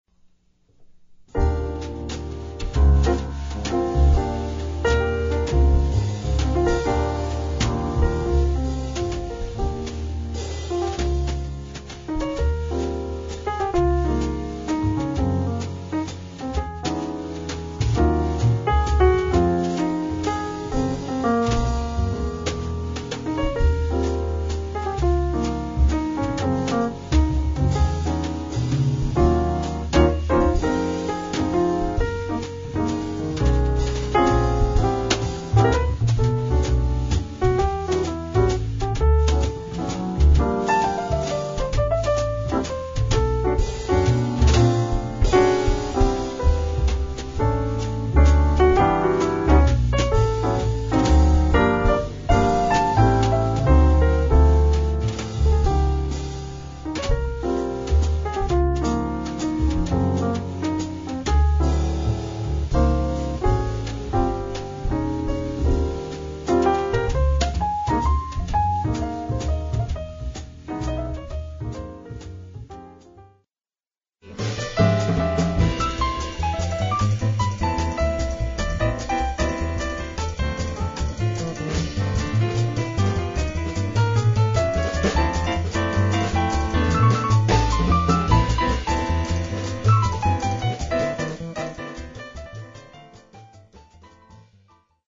The resulting sound ranges from intimate to exciting.